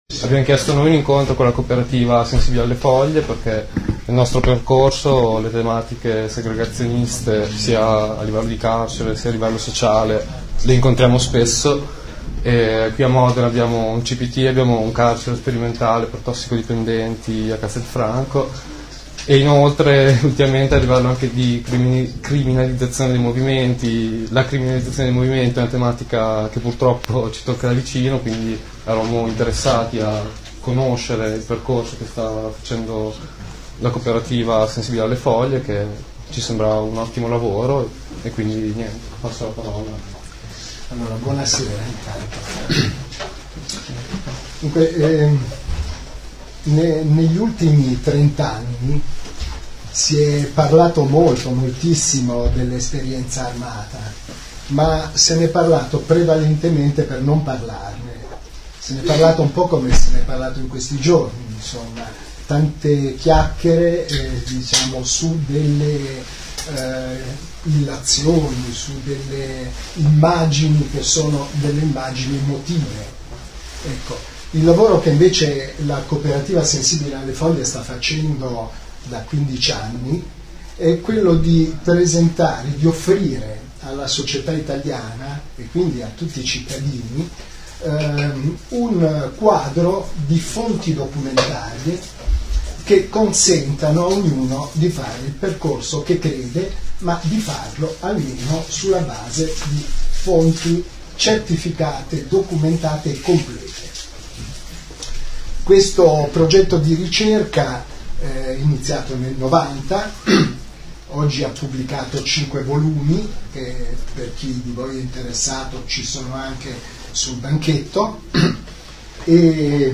Erano tante le persone che sabato 31 marzo affollavano la sede del Laboratorio ‘S.C.O.S.S.A.’ di Via Carteria.
Renato Curcio, ex-fondatore delle Brigate Rosse insieme a Margherita Cagol e ad Alberto Franceschini, è stato invitato nella nostra città per presentare il volume ‘Il carcere speciale’ pubblicato dalla case editrice ‘Sensibili alle Foglie’.